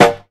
Boom Acoustic Snare Sample D# Key 159.wav
Royality free snare drum sample tuned to the D# note. Loudest frequency: 1054Hz
boom-acoustic-snare-sample-d-sharp-key-159-8Zt.ogg